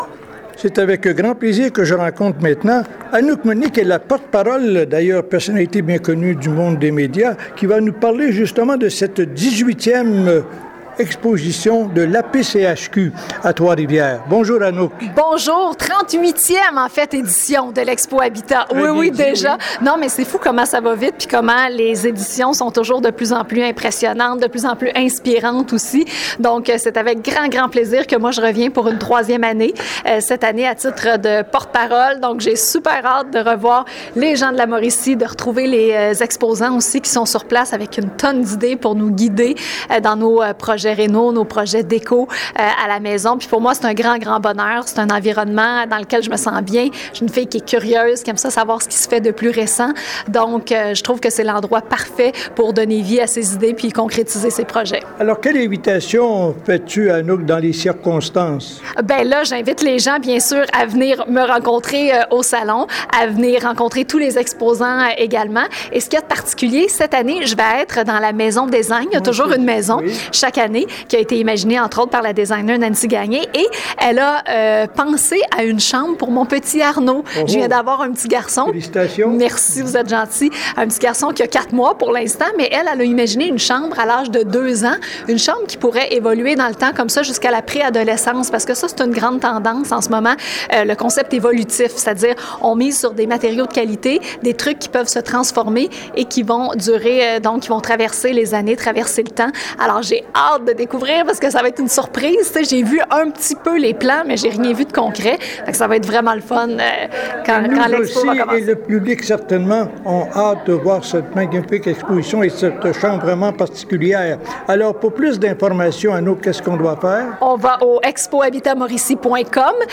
Voici l’entrevue avec Anouk Meunier:
expo-habitat-2020-avec-anouk-meunier.mp3